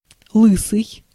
Ääntäminen
Synonyymit плешивый безволосый голый Ääntäminen Tuntematon aksentti: IPA: /ˈlɨsɨj/ Haettu sana löytyi näillä lähdekielillä: venäjä Käännös Ääninäyte Adjektiivit 1. bald US 2. bald-headed Translitterointi: lysyi.